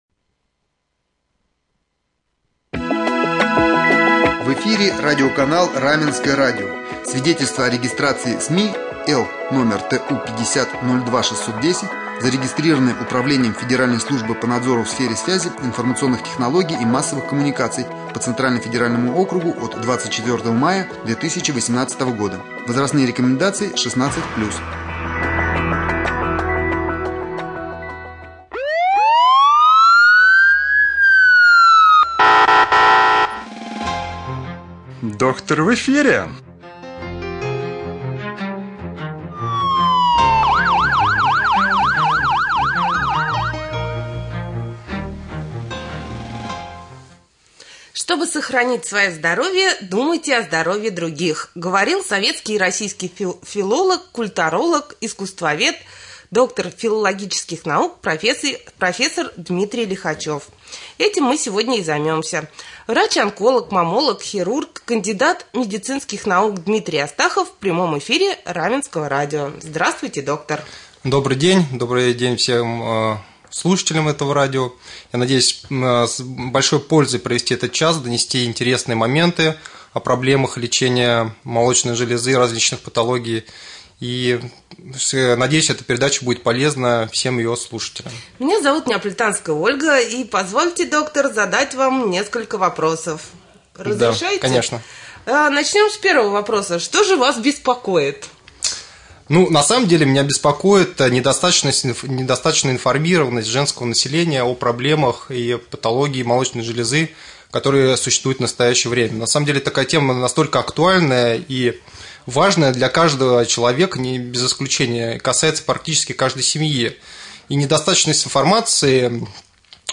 Гость часа